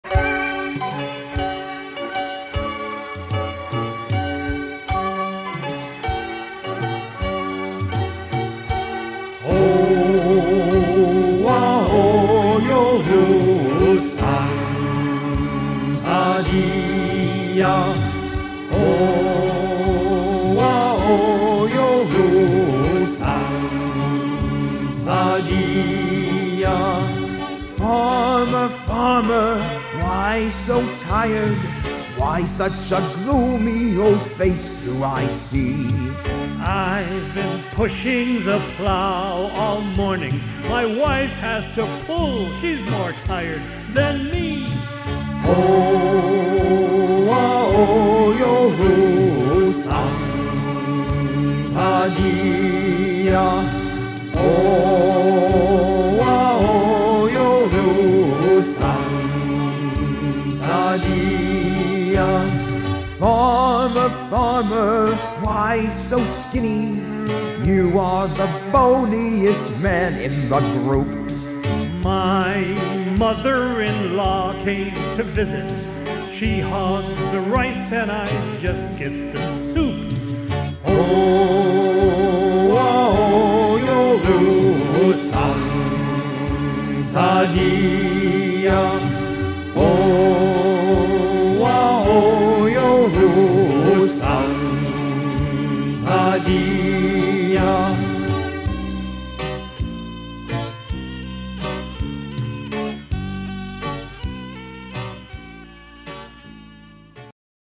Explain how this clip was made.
Seoul Olympics Arts Festival '88